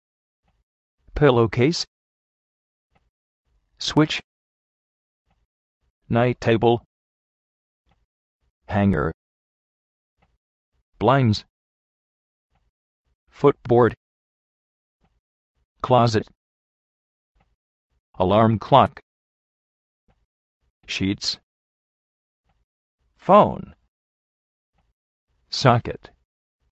pílou-kéis
náit téibl
jánguer
alárm clok
shí:ts